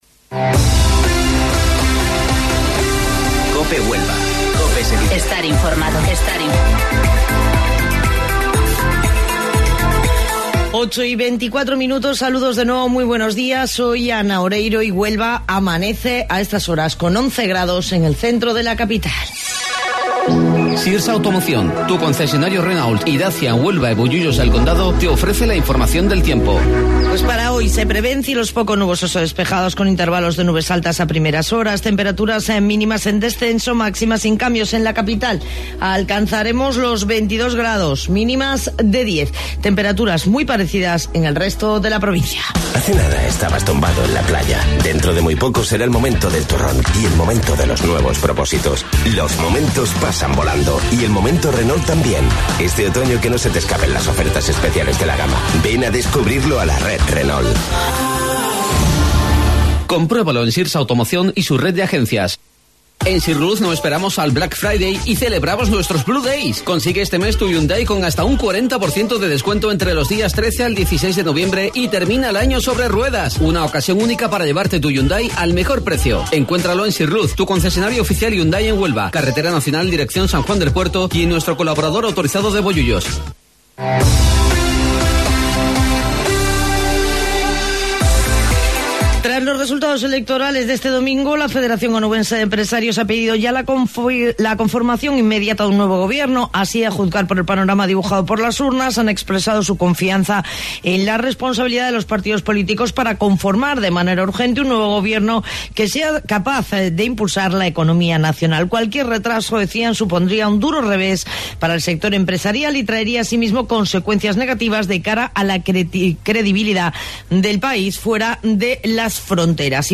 AUDIO: Informativo Local 08:25 del 12 de Noviembre